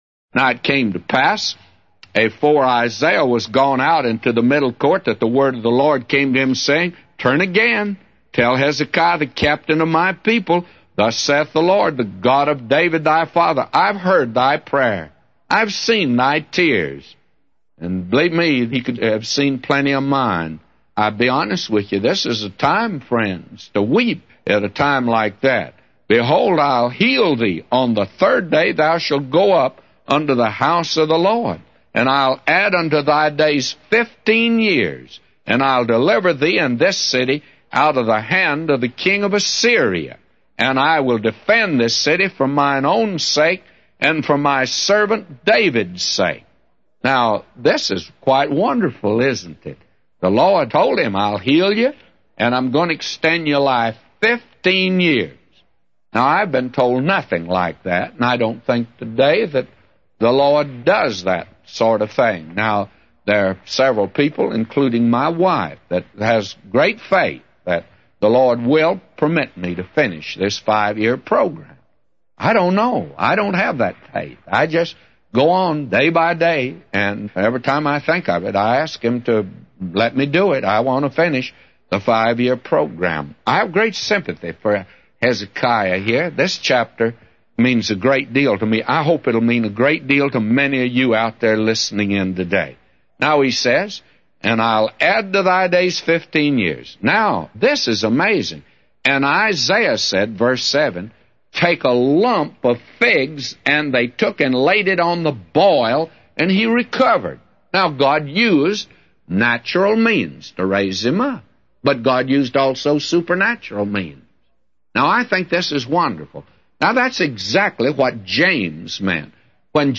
A Commentary By J Vernon MCgee For 2 Kings 20:4-999